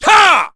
Chase-Vox_Attack2_kr.wav